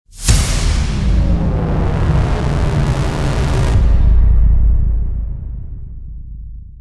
Звук замедления времени в кино